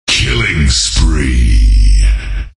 Я конечно не совсем по теме, но мне нужно найти quake звуки, только не стандартные и без музыки после звука.. а вот типа такого звука, который прикреплен к сообщению. только нужны все.
q11_sound_killi.mp3